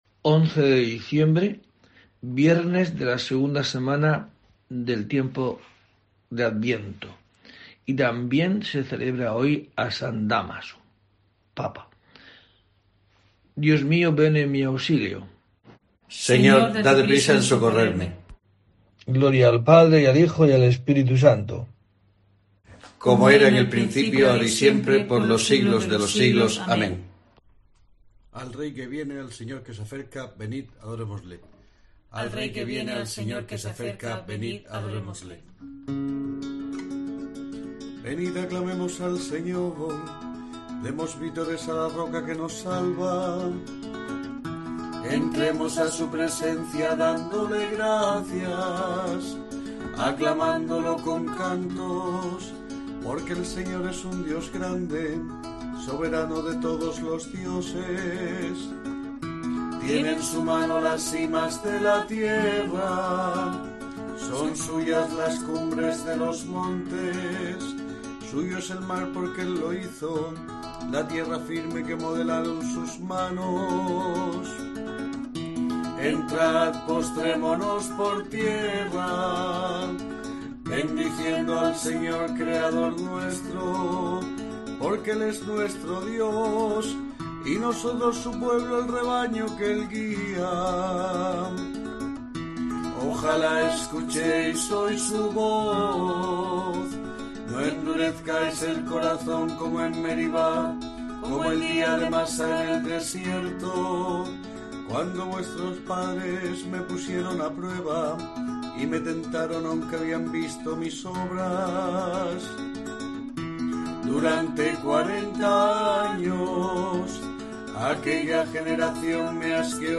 11 de diciembre: COPE te trae el rezo diario de los Laudes para acompañarte